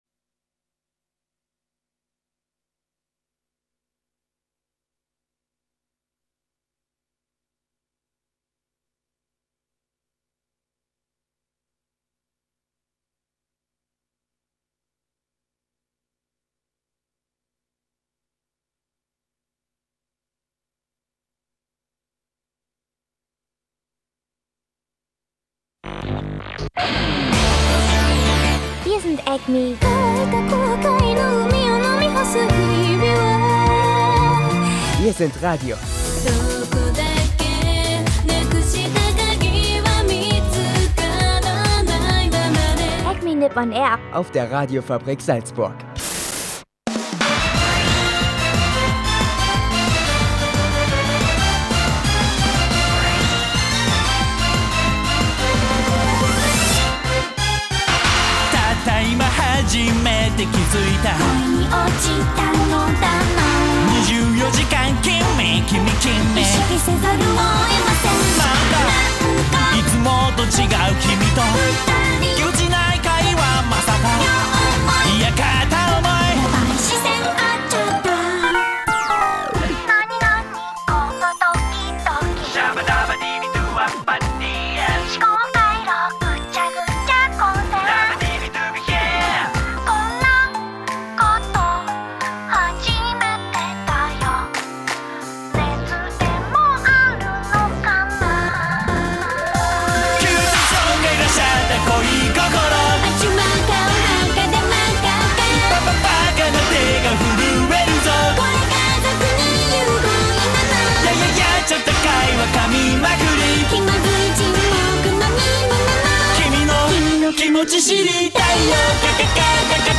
Dieses Mal gibt es viel Musik aus Japan und dazu einige News aus Japan und zu Detektiv Conan und Totoro, dazu ein Animetipp zum Comedy-Anime der Season.